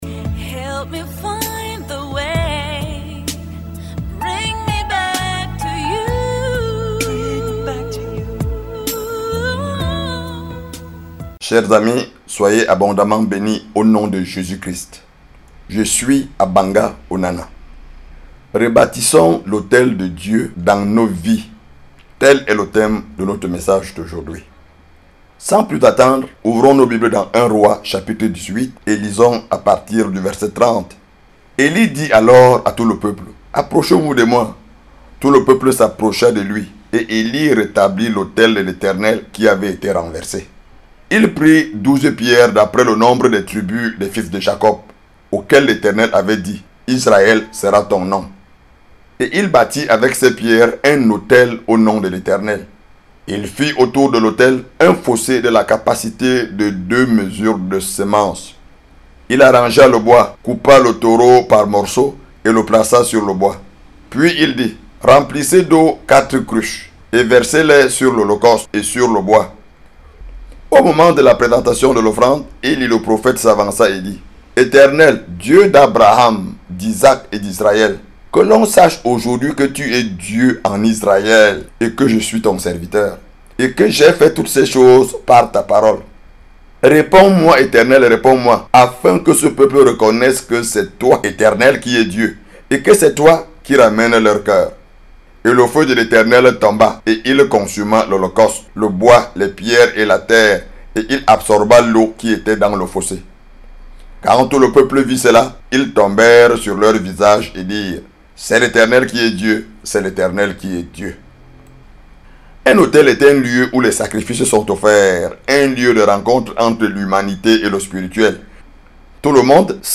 Évangéliste